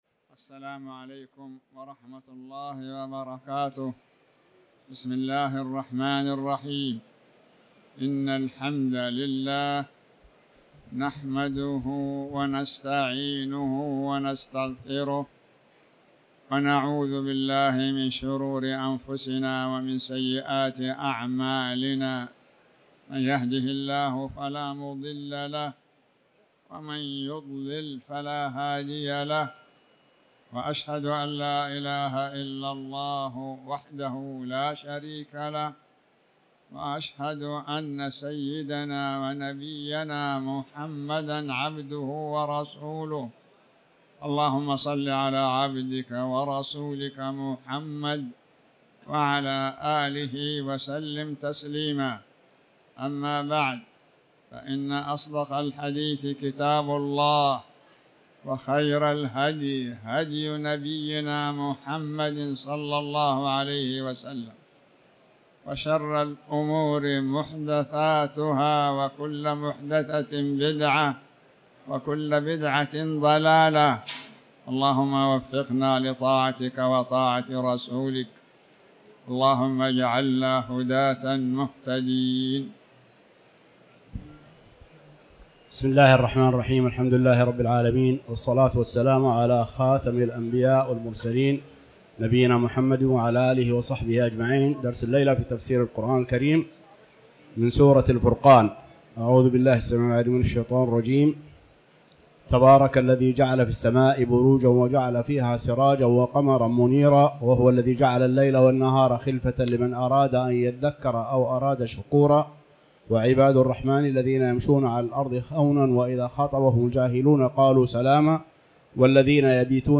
تاريخ النشر ١٤ شوال ١٤٤٠ هـ المكان: المسجد الحرام الشيخ